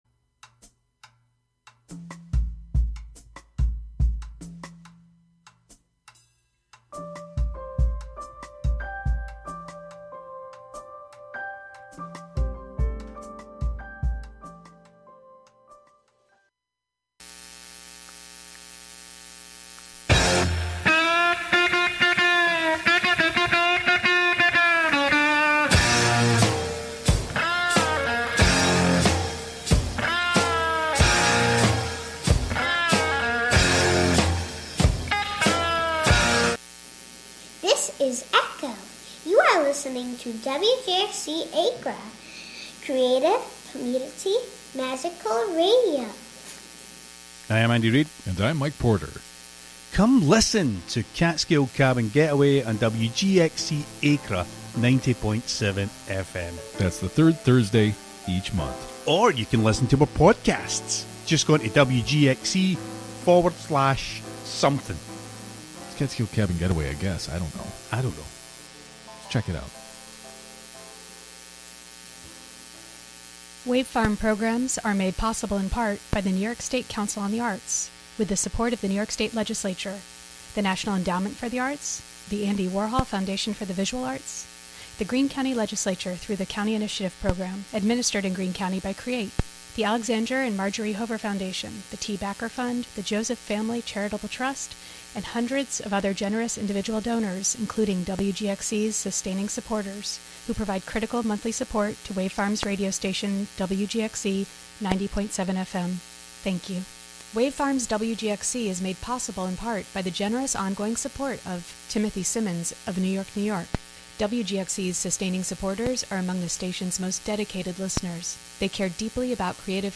both acoustic and electric